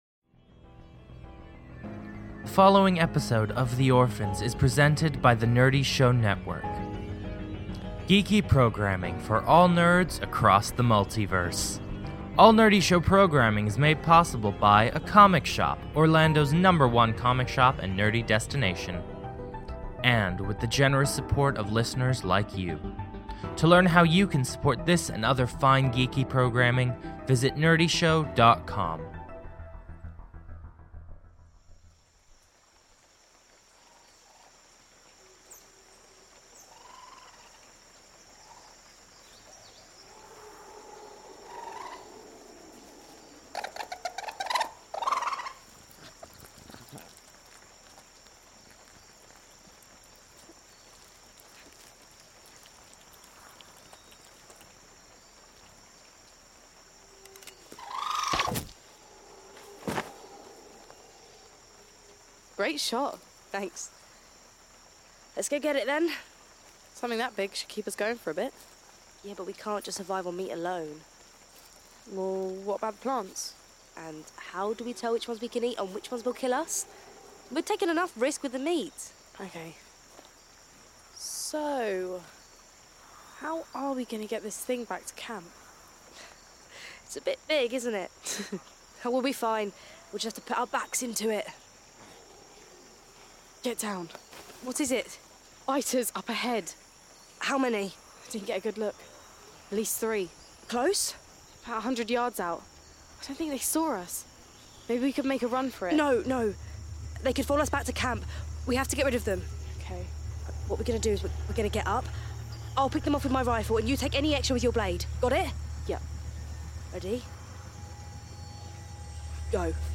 The Orphans is a cinematic audio drama chronicling the castaways of downed starship, The Venture - Stranded on a hostile planet, struggling to remember how they arrived and who they are.